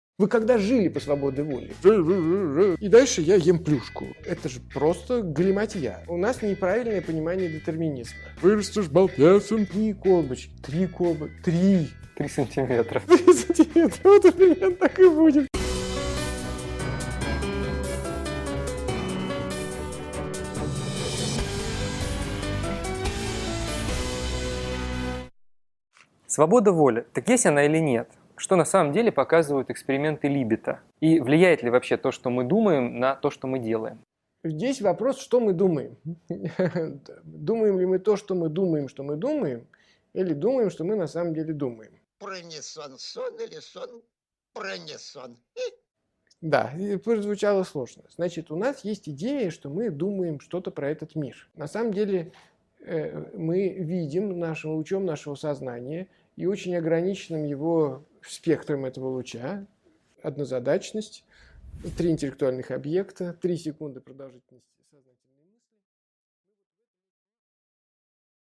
Автор Андрей Курпатов Читает аудиокнигу Андрей Курпатов.